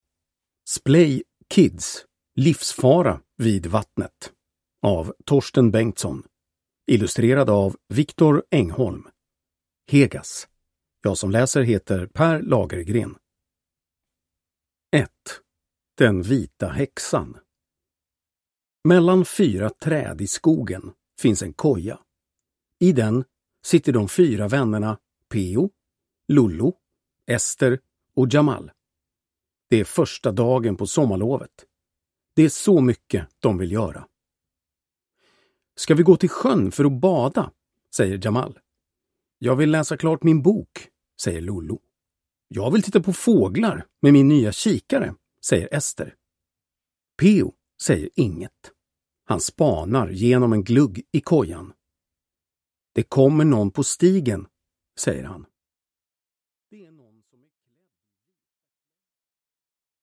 Livsfara vid vattnet – Ljudbok